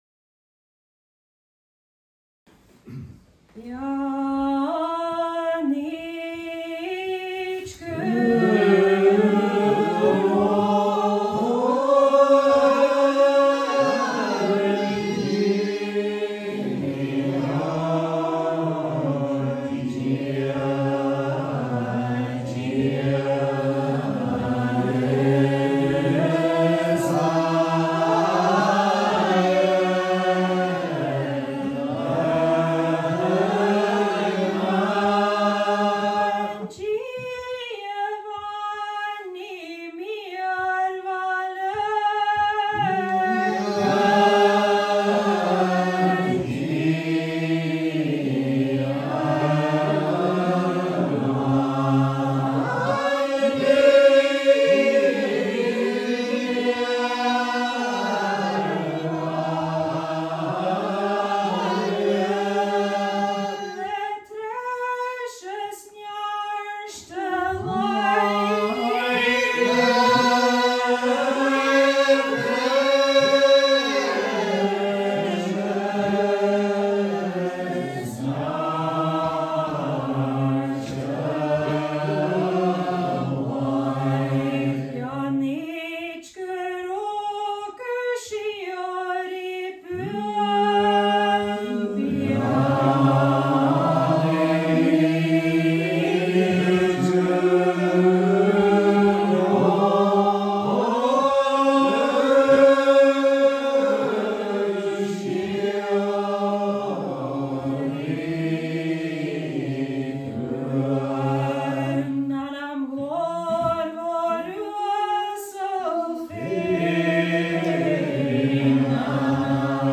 A’ Seinn nan Sailm Gaelic Psalmody